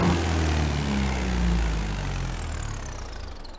Home gmod sound vehicles tdmcars beetle
throttle_off.mp3